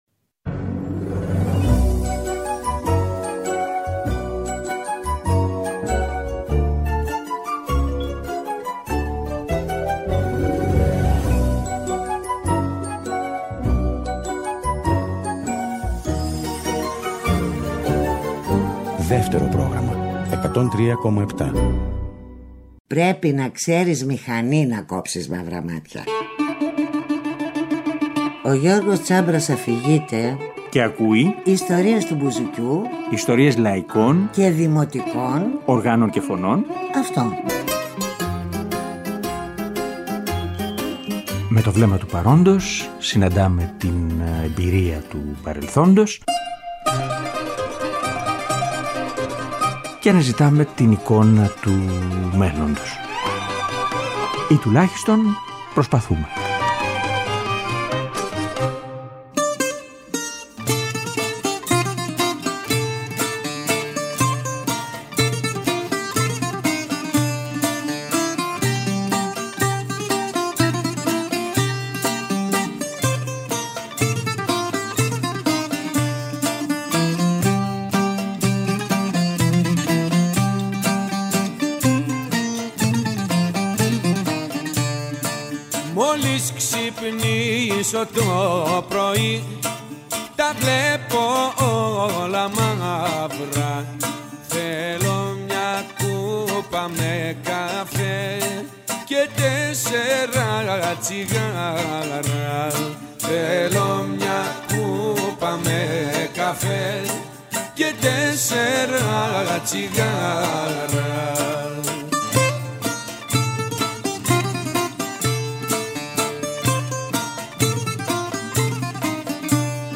Με μνήμες, τραγούδια και σόλο.